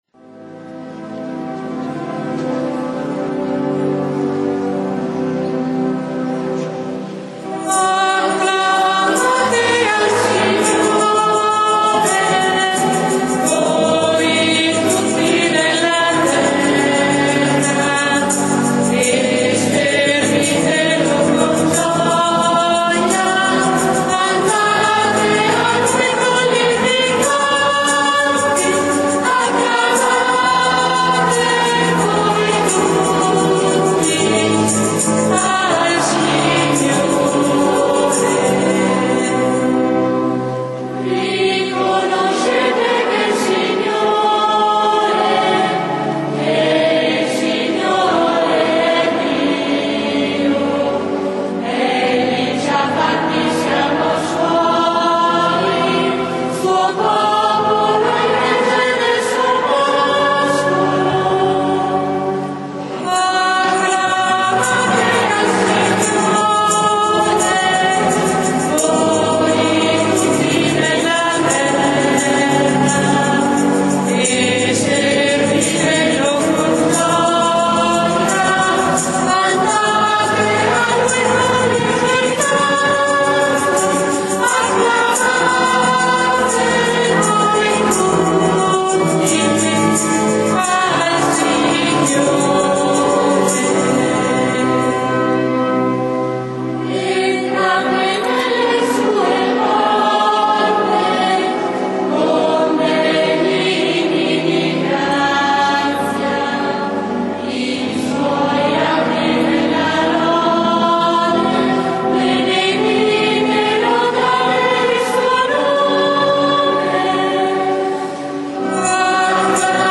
canto: Acclamate al Signore